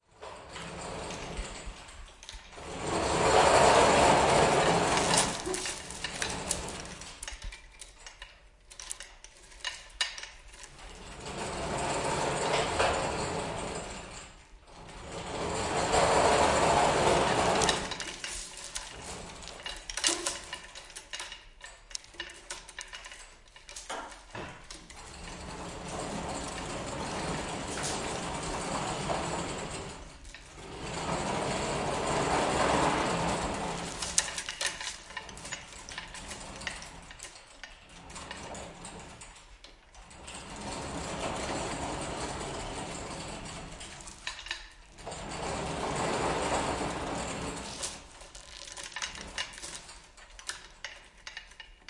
金属车间 " 金属车间提升机的链条在轨道上发出厚重的响声
描述：金属车间葫芦链上厚厚的拨浪鼓拉上track.flac
Tag: 提升机 轨道 金属 拨浪鼓 店铺